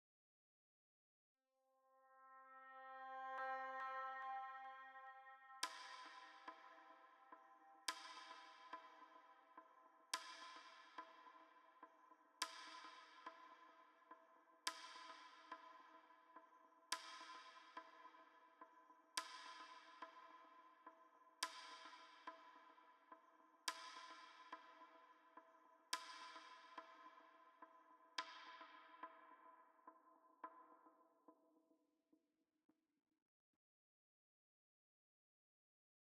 STest1_1kHz.flac